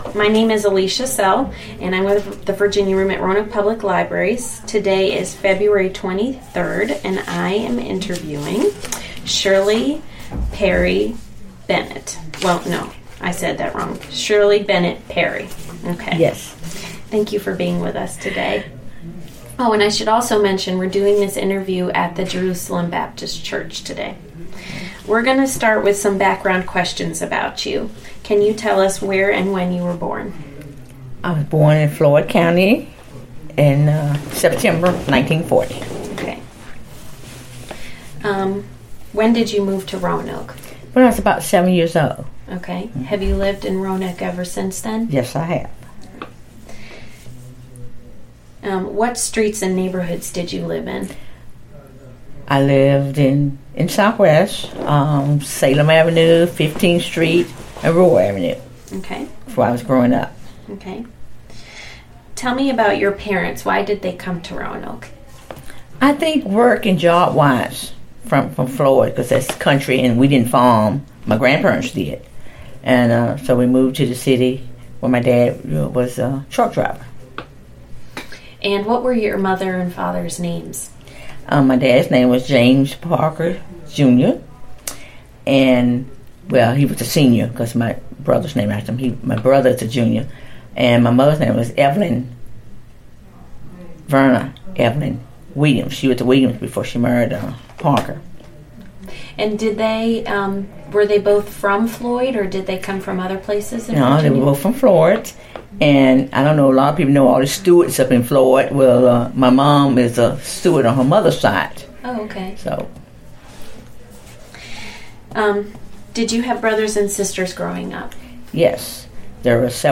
Location: Jerusalem Baptist Church
Neighborhood Oral History Project